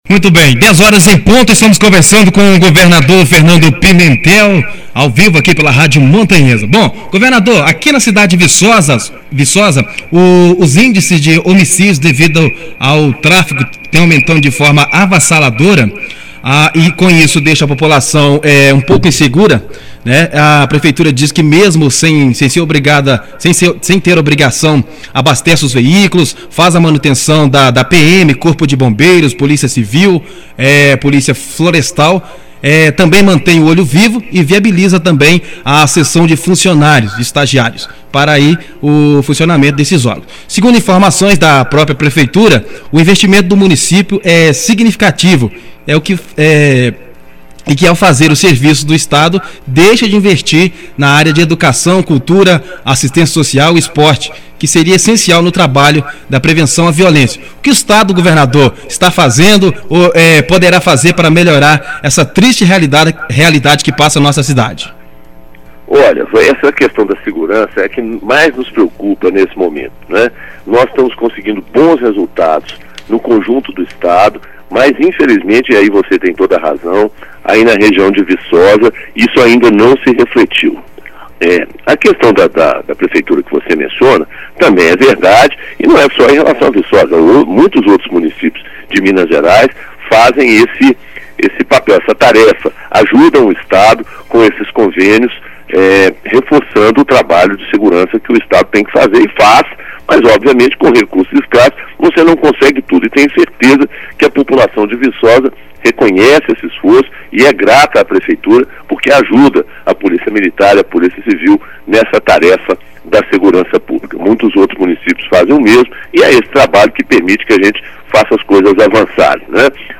GOVERNADOR DE MG FERNANDO PIMENTEL CONCEDE ENTREVISTA EXCLUSIVA À RÁDIO MONTANHESA